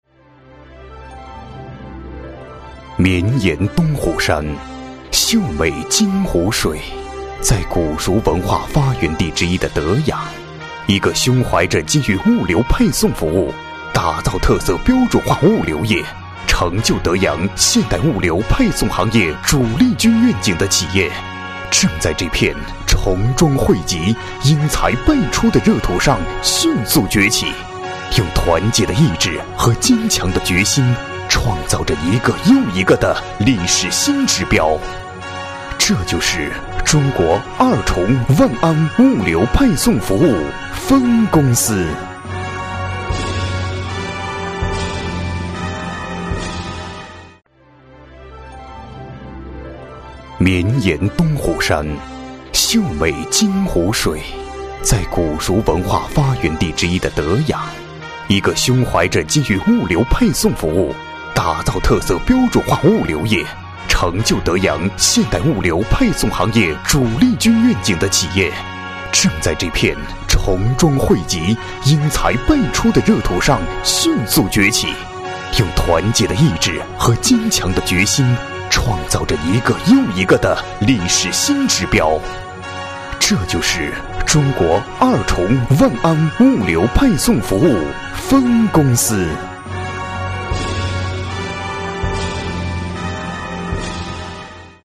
国语中年激情激昂 、大气浑厚磁性 、积极向上 、男广告 、300元/条男S337 国语 男声 广告-京汉1903-随性、自然 激情激昂|大气浑厚磁性|积极向上